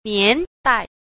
9. 年代 – niándài – niên đại
nian_dai.mp3